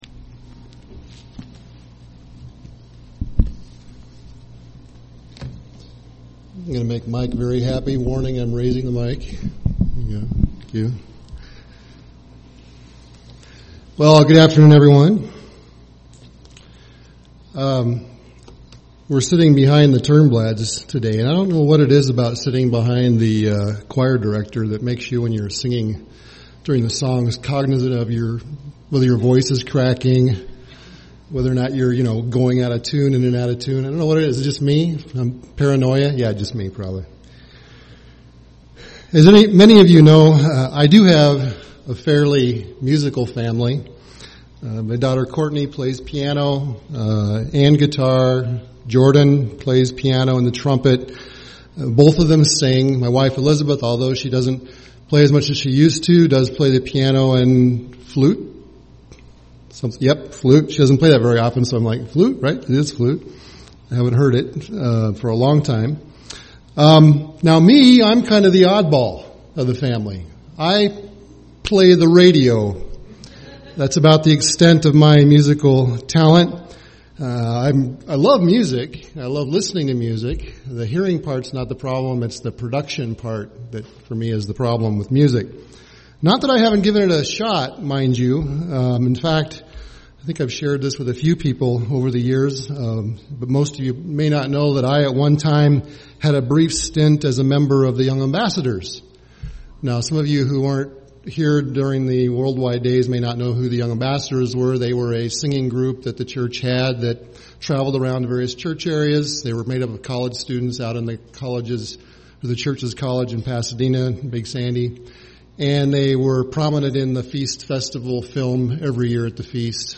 Given in Twin Cities, MN
UCG Sermon rejoicing weaknesses Studying the bible?